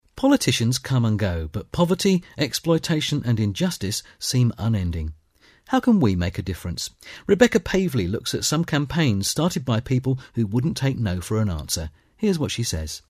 British English voiceover, mature voice, caring, authoritative.
Sprechprobe: Sonstiges (Muttersprache):
British English voiceover for commercials, documentaries, corporate videos and e-learning.